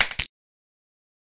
基础音效 " 菜单弹奏声
描述：我的手机上记录了由Flicking纸张创建的内容，并且没有添加任何效果，只进行了修剪。
标签： 游戏 菜单 弗里克
声道立体声